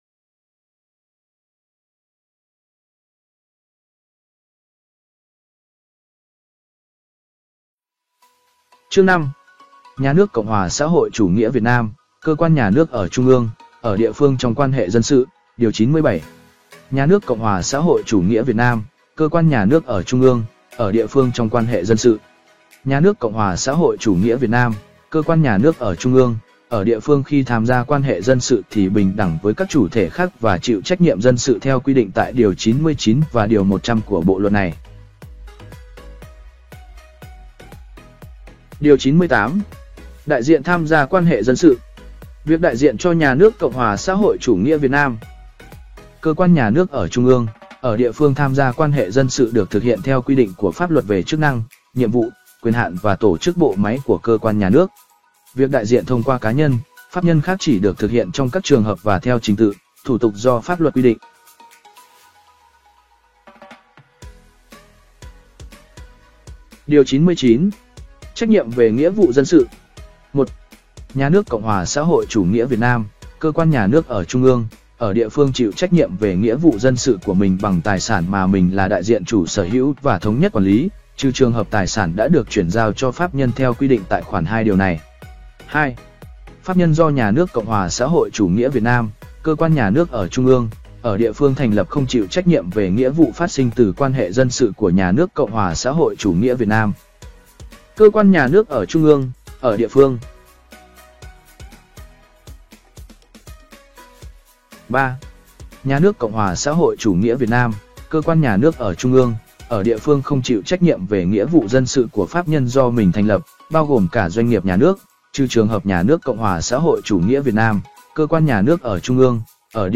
Sách nói | Bộ luật dân sự